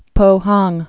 (pōhäng)